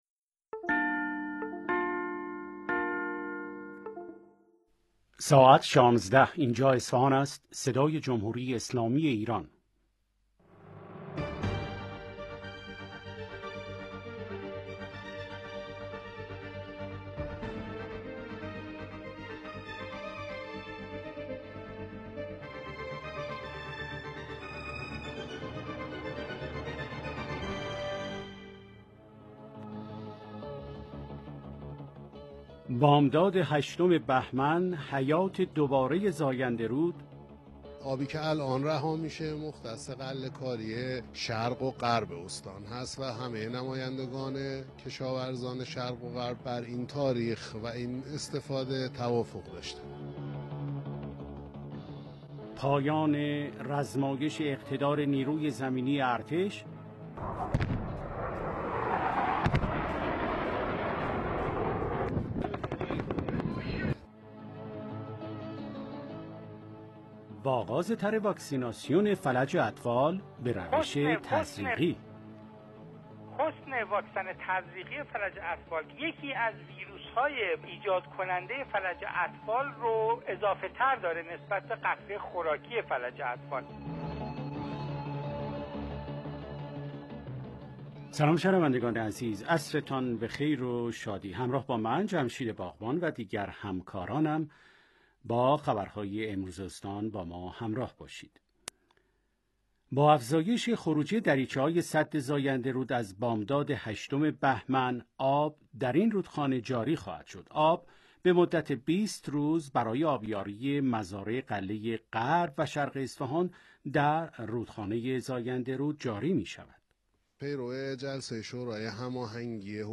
انعکاس مهمترین رویدادهای استان از بخش خبری 16 رادیو